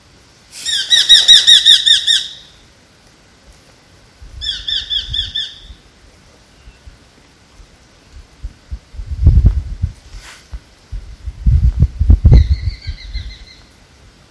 Bat Falcon (Falco rufigularis)
Location or protected area: Parque Provincial Caá Yarí
Condition: Wild
Certainty: Photographed, Recorded vocal
Halcon-negro-chico-Caa-Yari.mp3